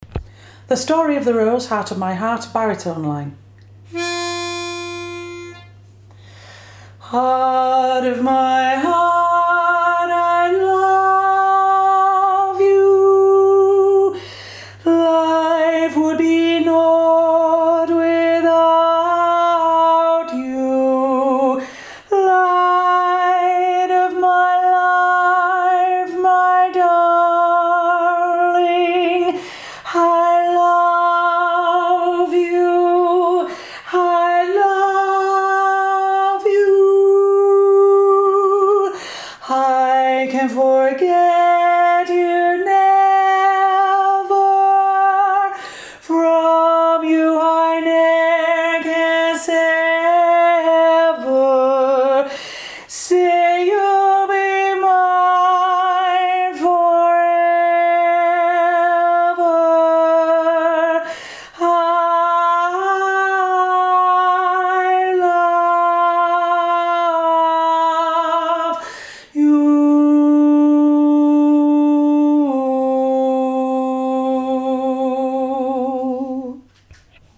Heart-of-My-Heart-Baritone.mp3